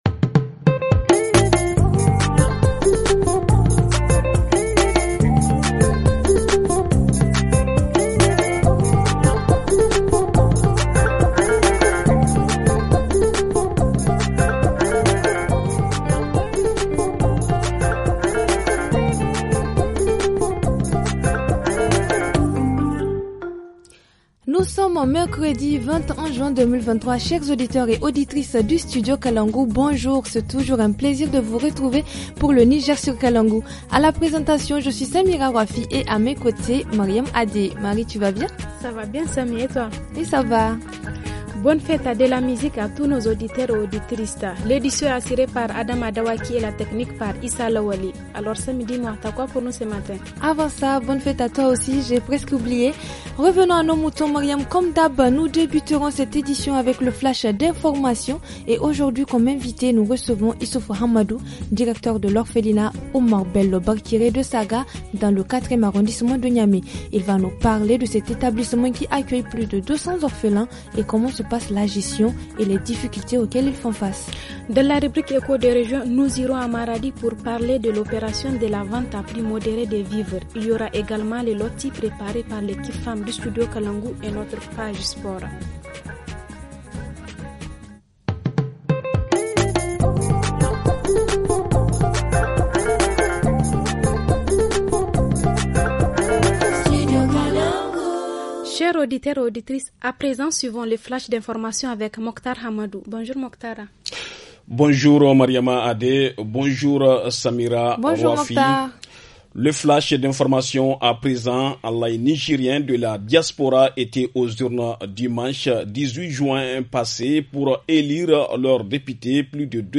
–Reportage Région : A Maradi, opération vente de céréales à prix modérés ;